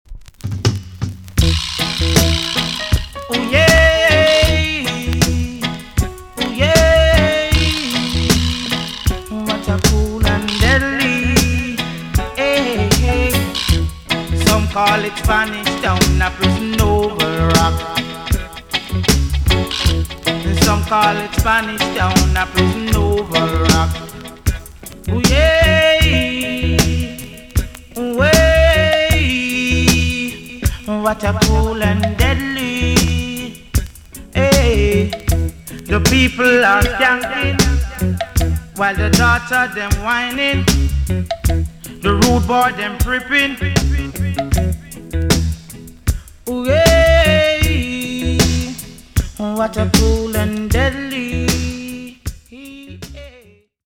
TOP >DISCO45 >80'S 90'S DANCEHALL
B.SIDE Dub Plate Mix
EX-~VG+ 少し軽いチリノイズがありますが音はキレイです。